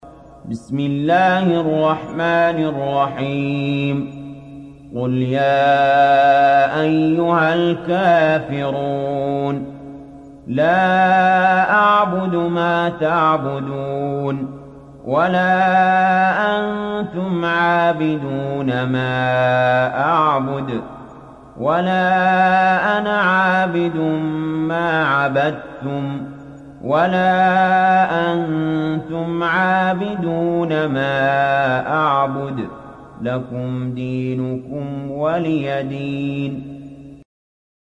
تحميل سورة الكافرون mp3 بصوت علي جابر برواية حفص عن عاصم, تحميل استماع القرآن الكريم على الجوال mp3 كاملا بروابط مباشرة وسريعة